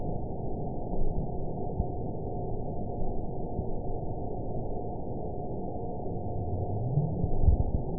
event 920395 date 03/23/24 time 00:46:33 GMT (1 month, 1 week ago) score 9.10 location TSS-AB03 detected by nrw target species NRW annotations +NRW Spectrogram: Frequency (kHz) vs. Time (s) audio not available .wav